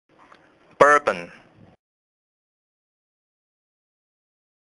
老美都是這麼唸！
這才是道地的美式發音喔！
“bour”不可讀成/ bur /，而是像“ber”一樣的//音。